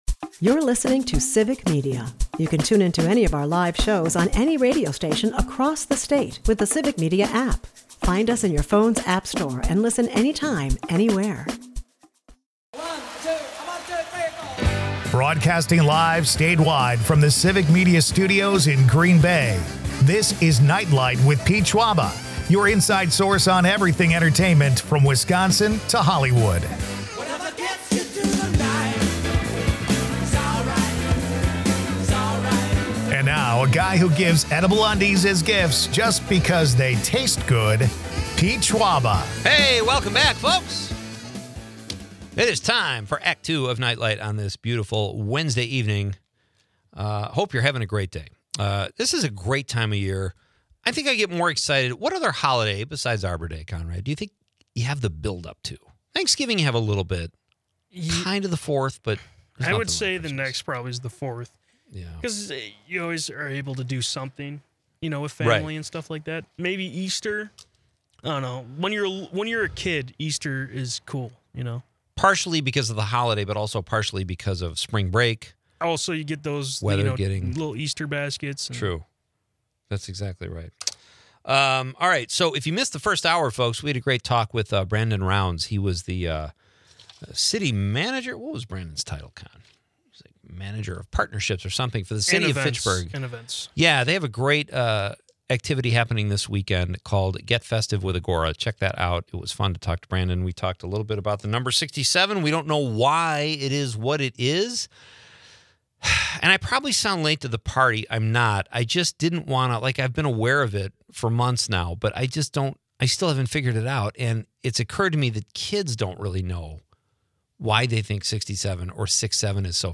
Listeners contribute to the question of the night: 'What do you want for Christmas?' with heartwarming and humorous responses. The episode also briefly touches on the new James L. Brooks film and its critical reception.